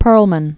(pûrlmən), Itzhak Born 1945.